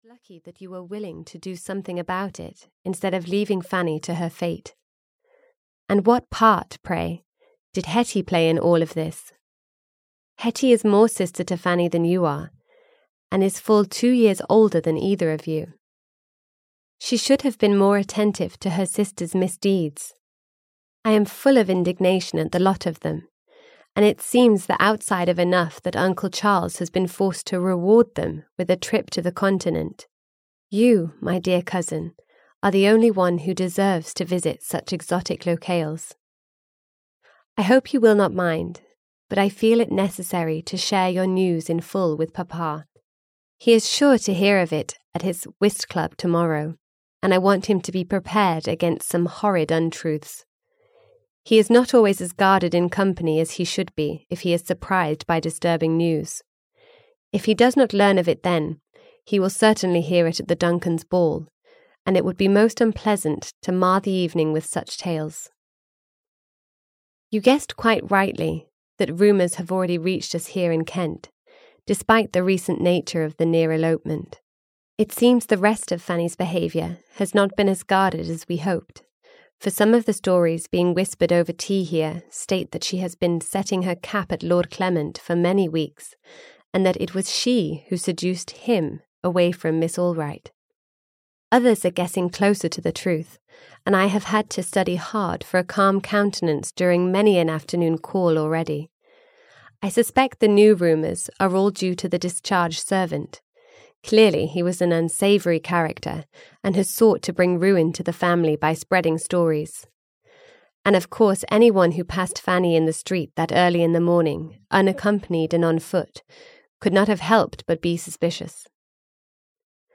Glass Roses (EN) audiokniha
Ukázka z knihy